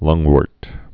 (lŭngwûrt, -wôrt)